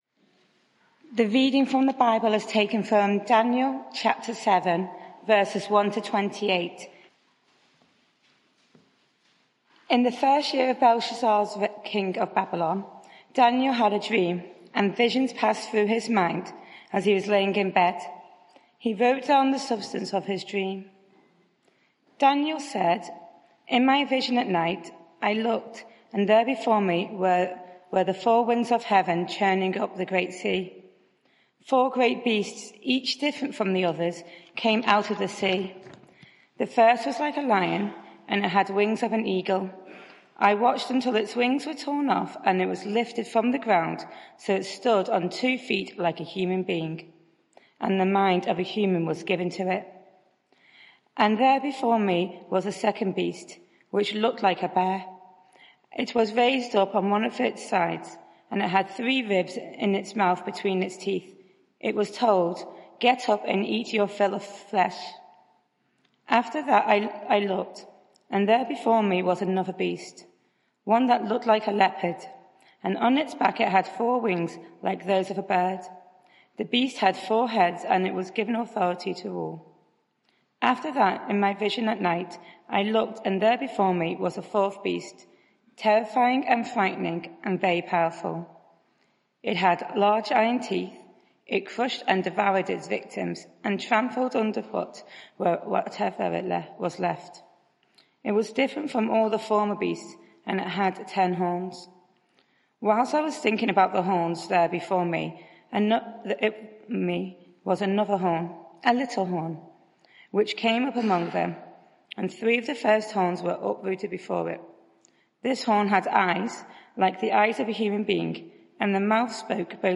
Media for 6:30pm Service on Sun 18th Jun 2023 18:30 Speaker
Daniel 7 Sermon Search the media library There are recordings here going back several years.